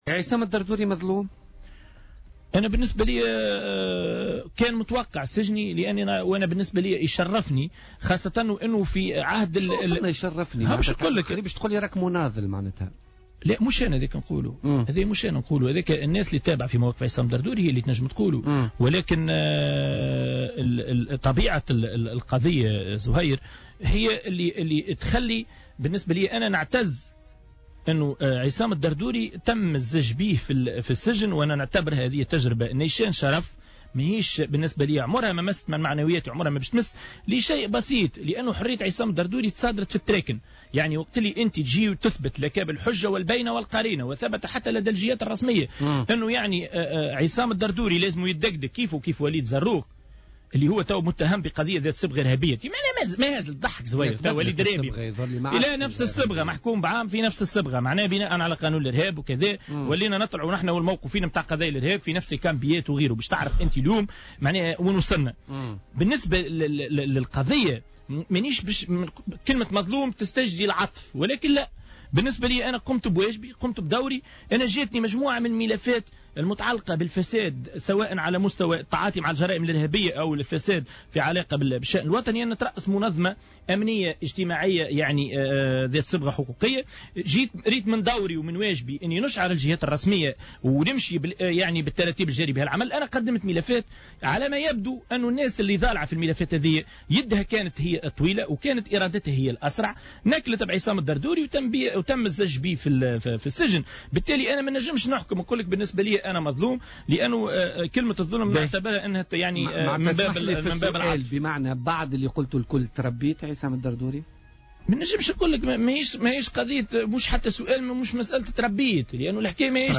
مداخلة له اليوم في برنامج "بوليتيكا"